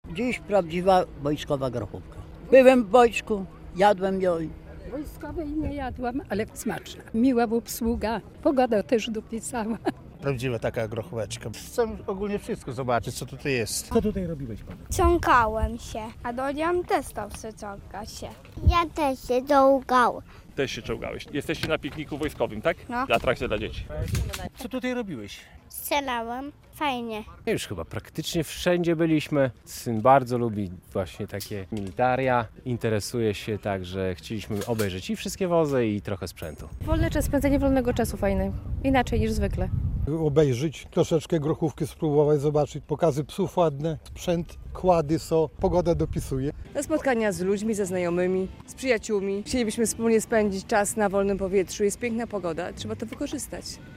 Piknik Militarny "Wojsko na swojsko" w Łomży - relacja